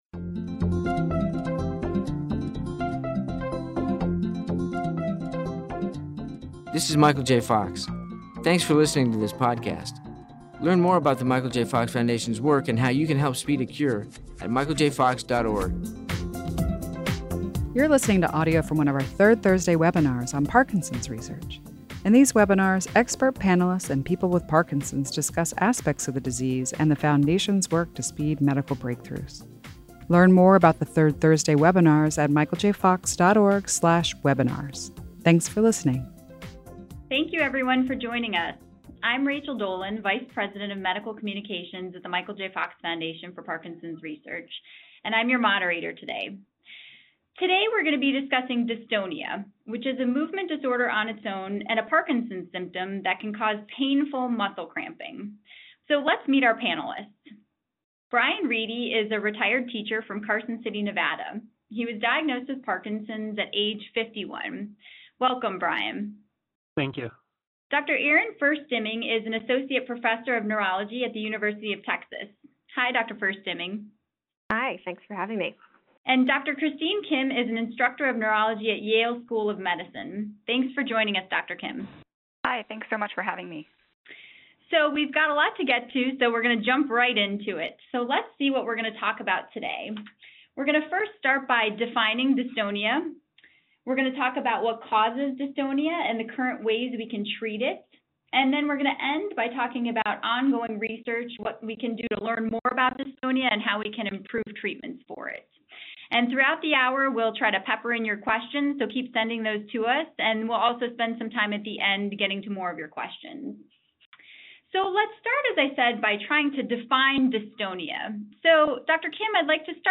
Listen to experts discuss the causes of dystonia and various treatment options.